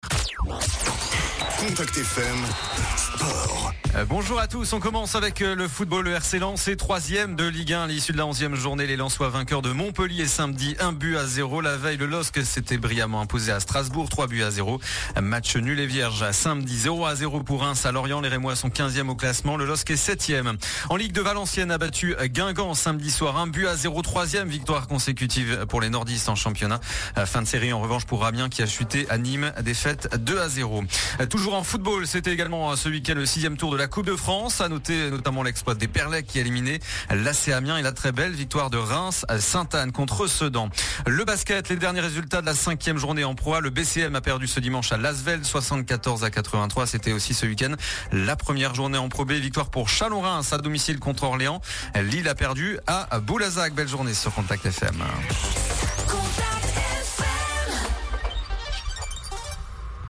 Le journal des sports du lundi 17 octobre